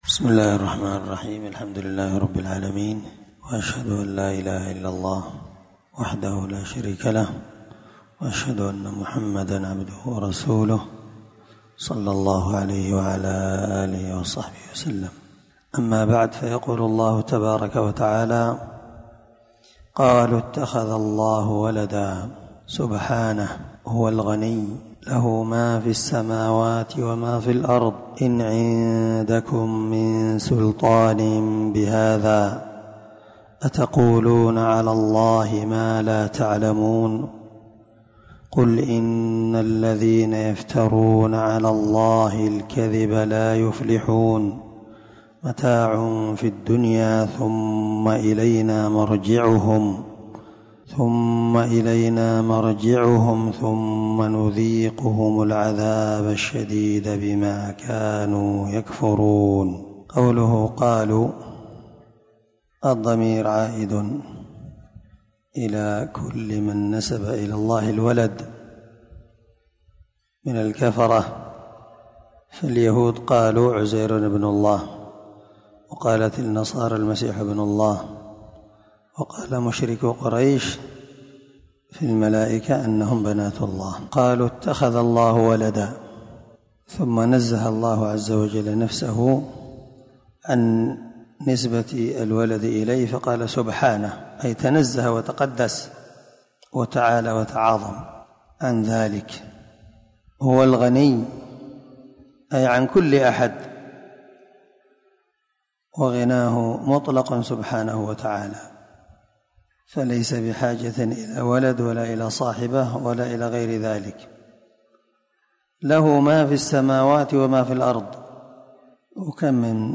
✒ دار الحديث- المَحاوِلة- الصبيحة.